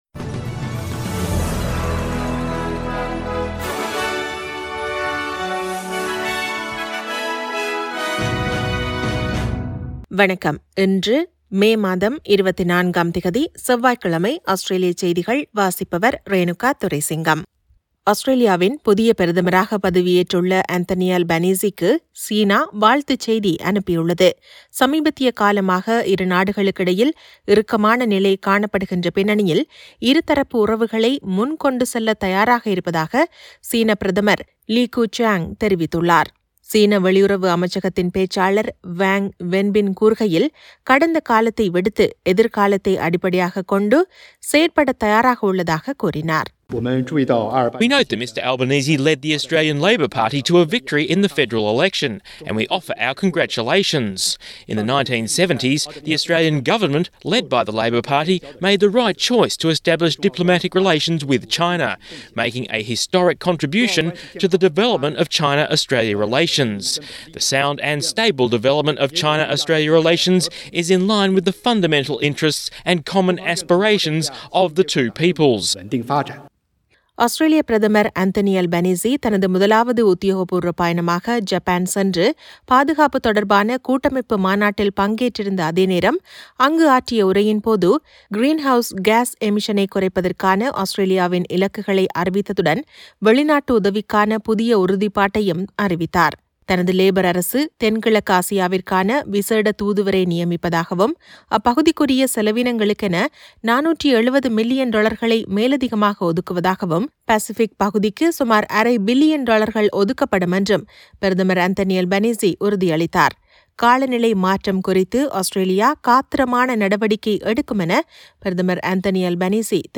Australian news bulletin for Tuesday 24 May 2022.